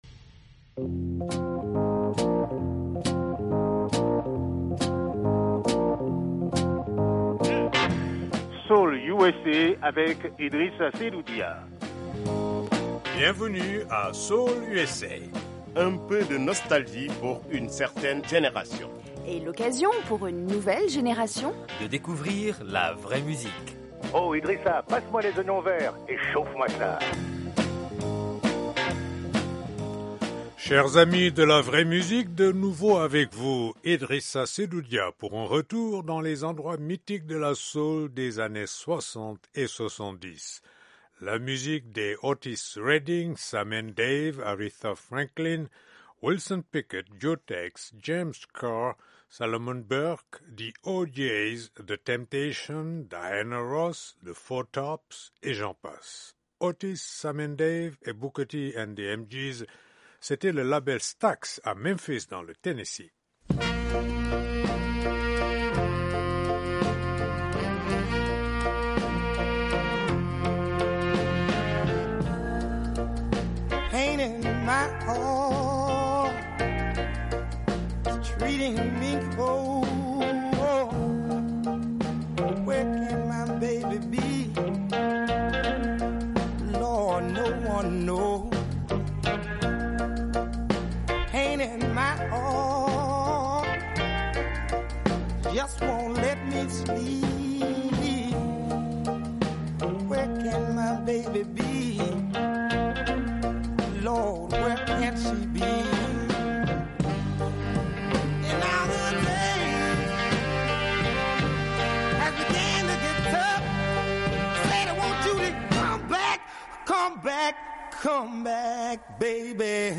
Les bons vieux tubes des années 60 et 70.